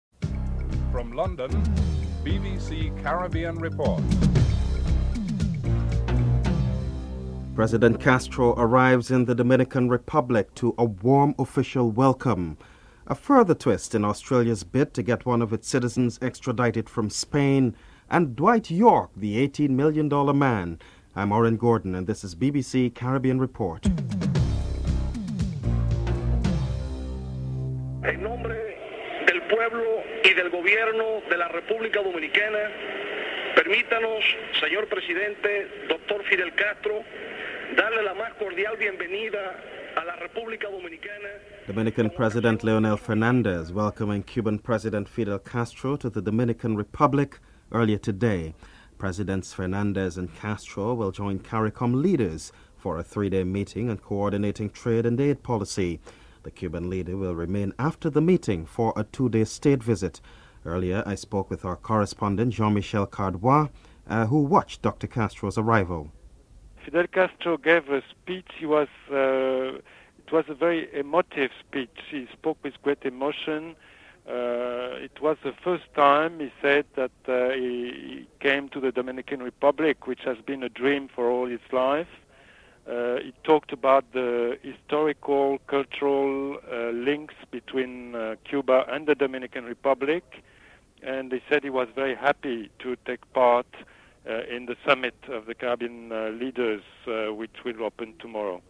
1. Headlines (00:00-00:26)
Opposition Leader Edward Seaga and Prime Minister P.J. Patterson are interviewed.